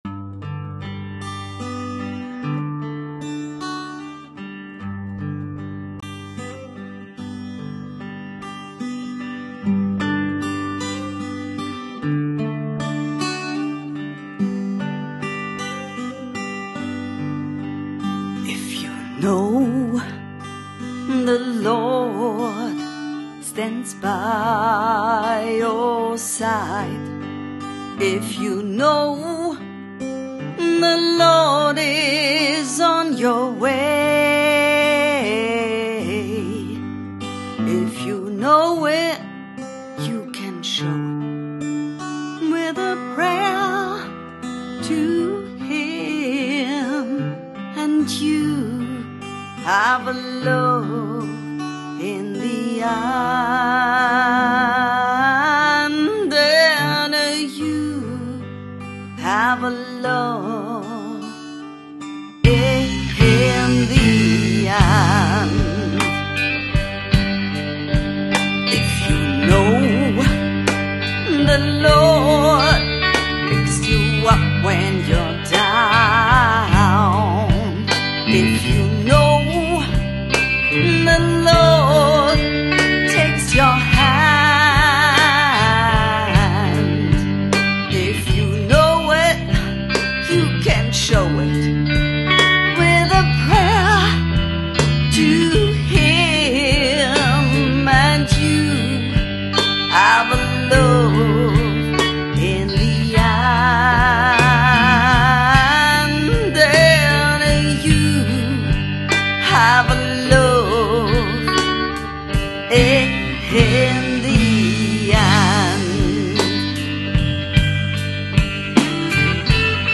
Folk und Rock, Soul und Gospel sind die Säulen